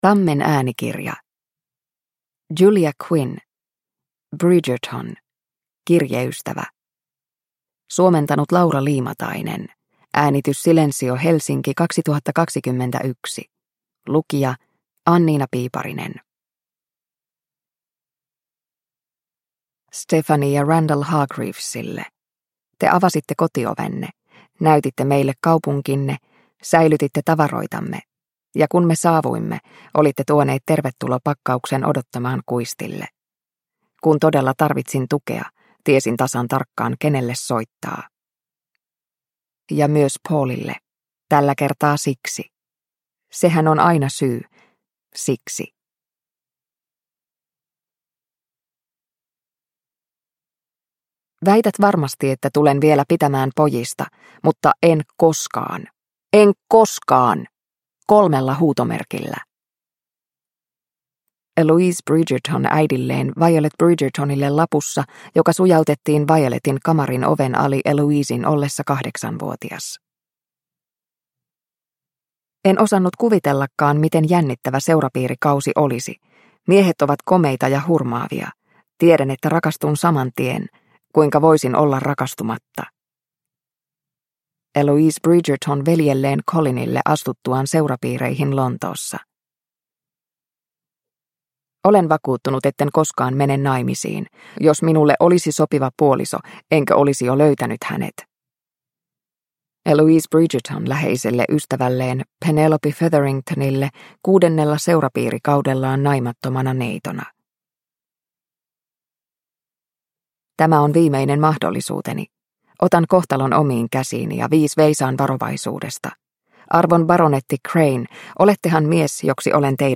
Bridgerton: Kirjeystävä – Ljudbok – Laddas ner